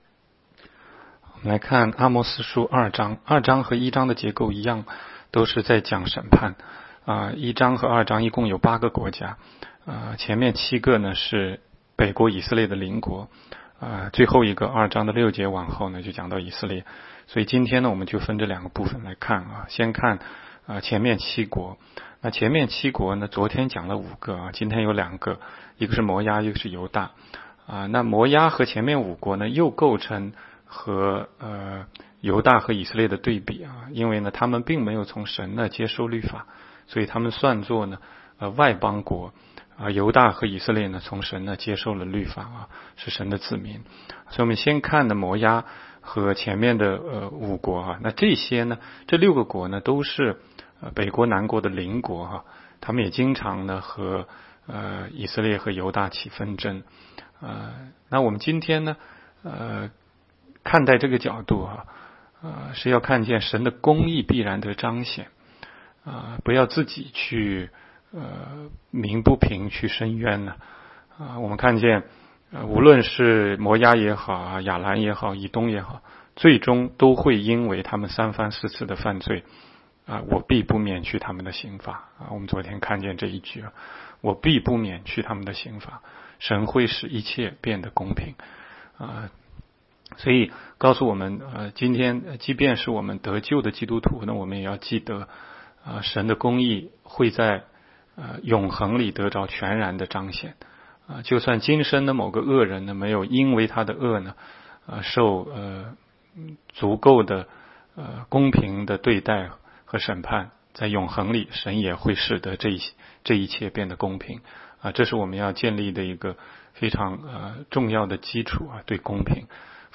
16街讲道录音 - 每日读经 -《阿摩司书》2章